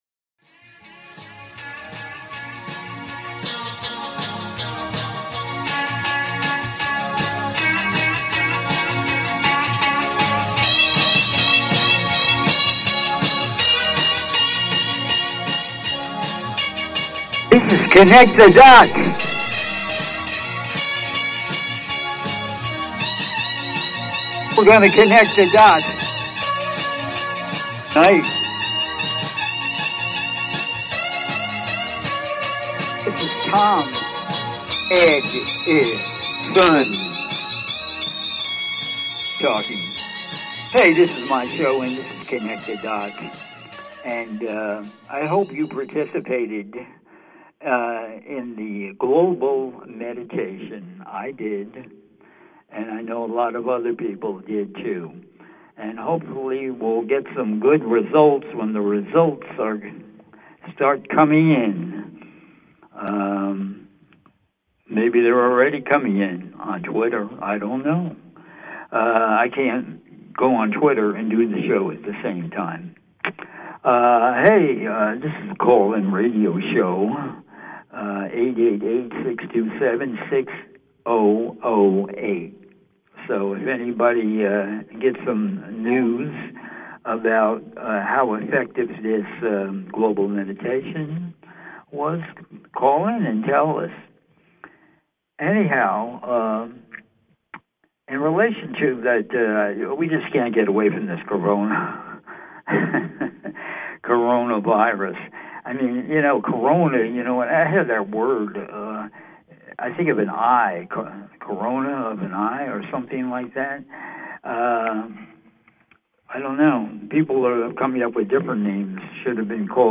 Talk Show Episode
"CONNECT THE DOTS" is a call in radio talk show